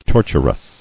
[tor·tur·ous]